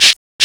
PERC LOOP5-L.wav